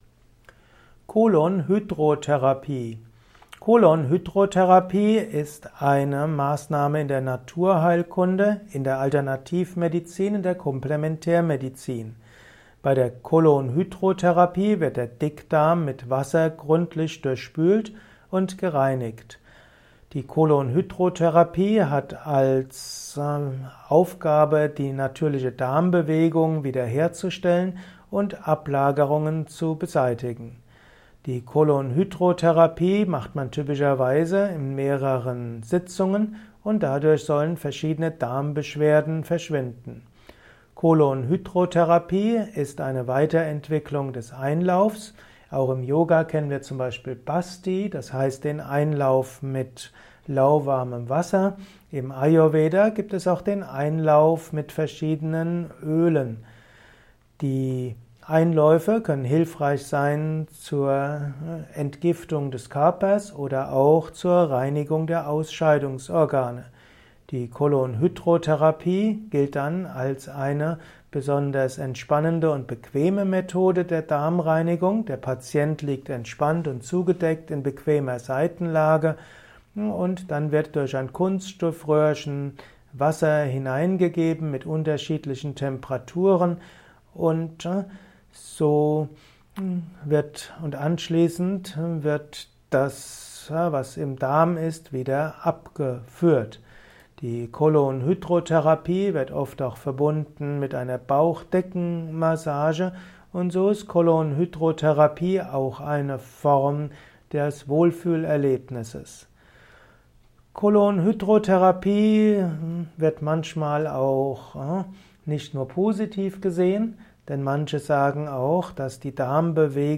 Erfahre mehr über die Colon-Hydro-Therapie diesem Kurzvortrag